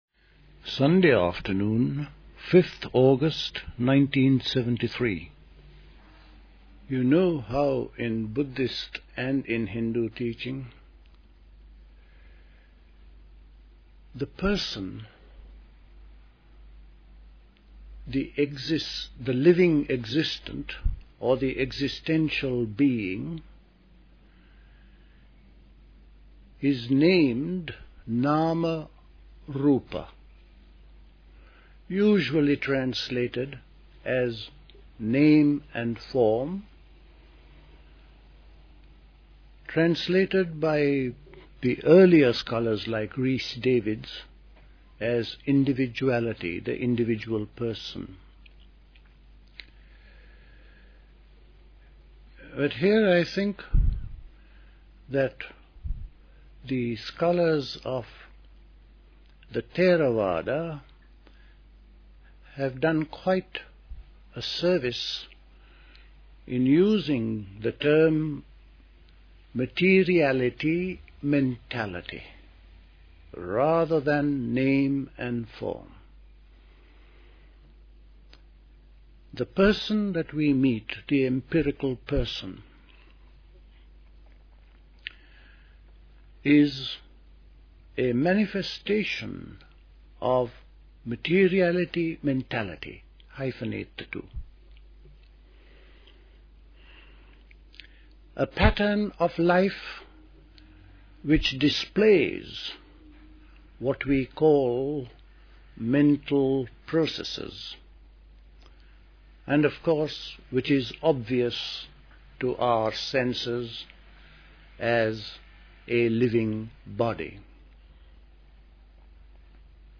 Recorded at the 1973 Dilkusha Summer School.